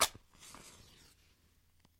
描述：记录吐痰液。使用Sennheiser 416和Sound Devices 552录制。
声道立体声